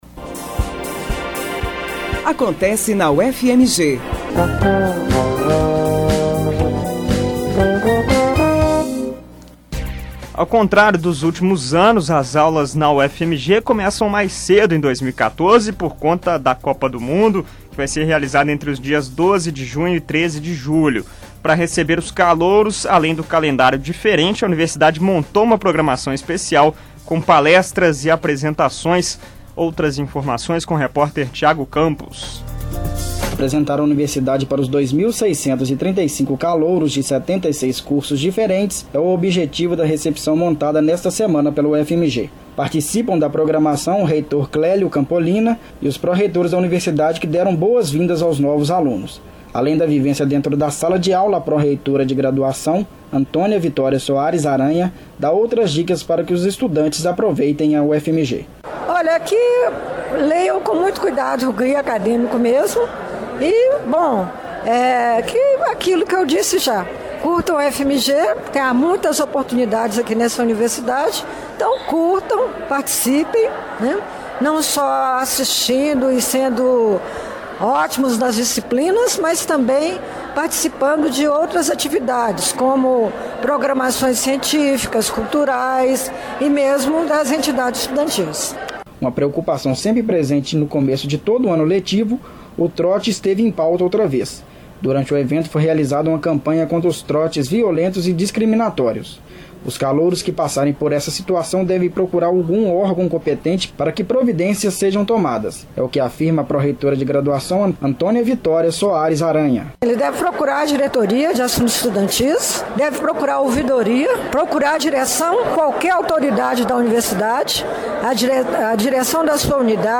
Reportagens da produção